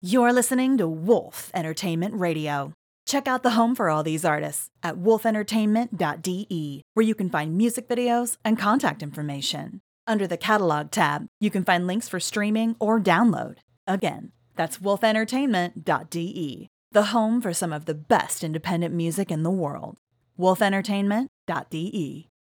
Wolf Entertainment Radio Bump
It’s smooth at the core, with just a hint of natural sparkle that keeps the sound lively, modern, and expressive.